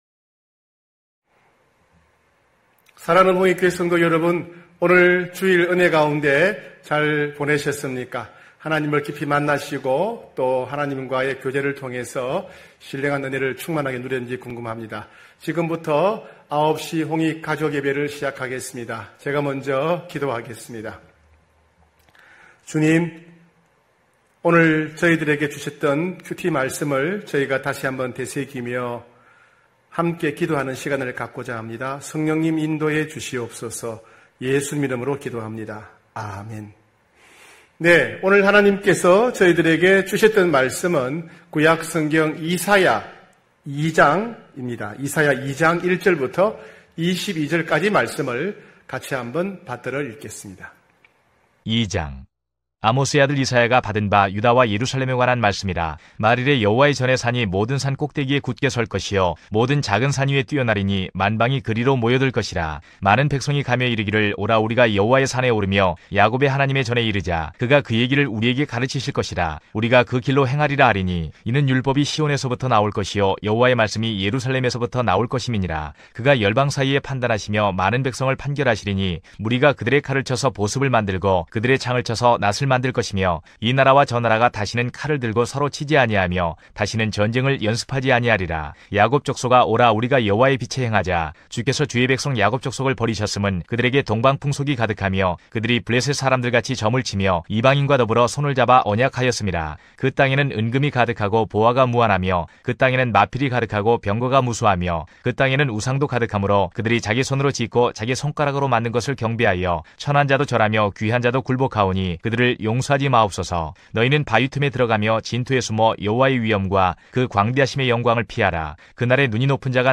9시홍익가족예배(7월12일).mp3